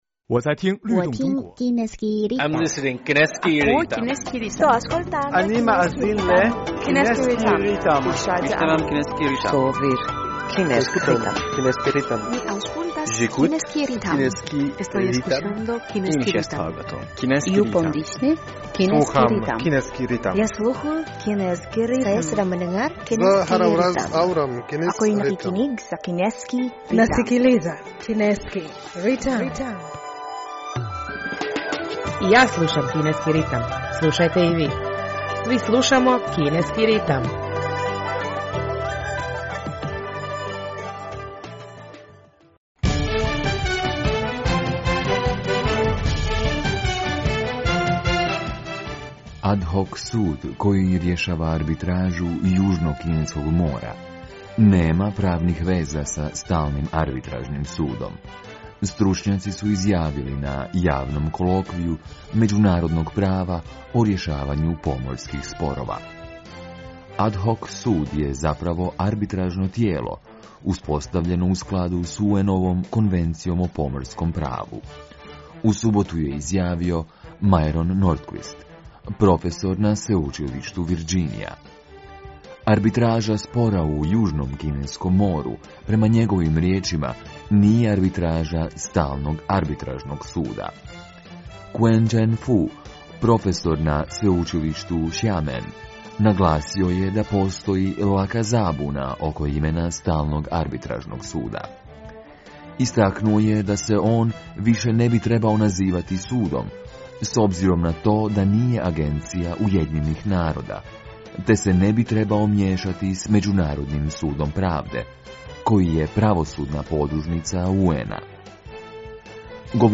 Slušate program na hrvatskom jeziku Kineskog radio Internacionala!